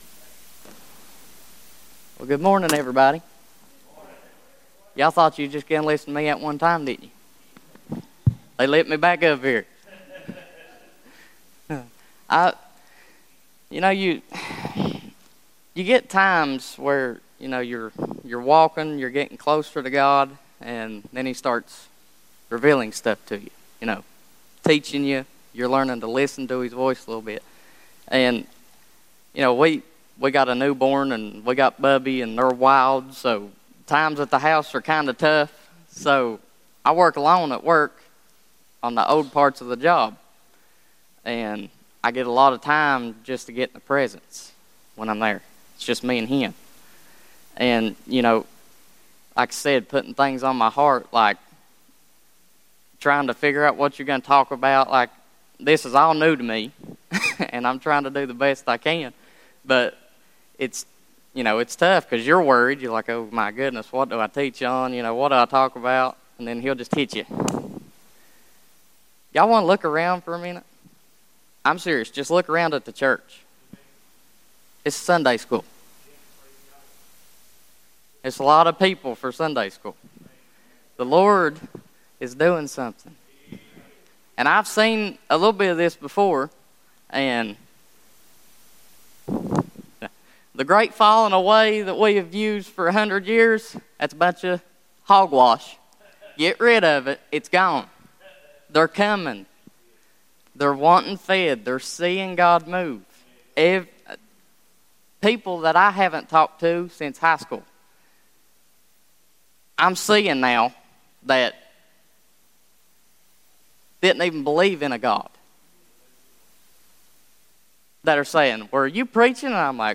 Sunday Morning Teaching
How we are to arm ourselves, Do the Job, and how That Knowing the Word of God will Enable us as well in Knowing Your Enemy. Enjoy this Young Brother and Be Enriched from his Teaching Today